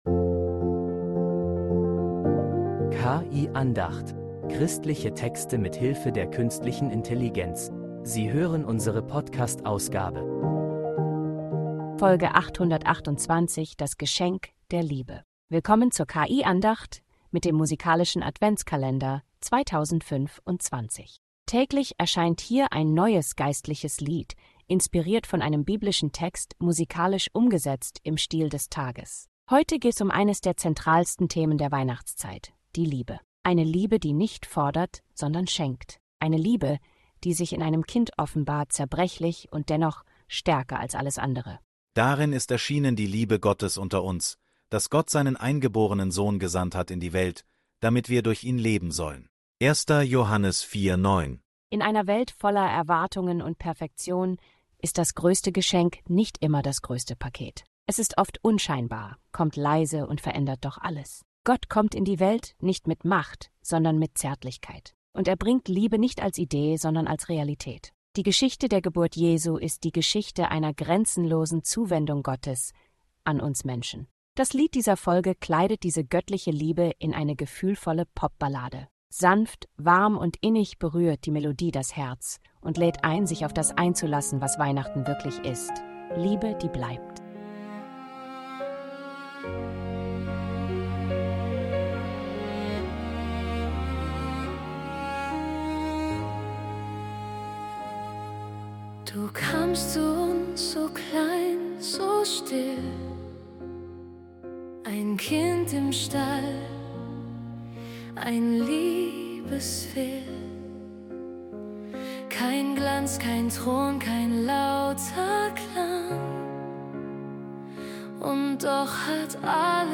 Ein gefühlvolles Lied erinnert uns daran, was wirklich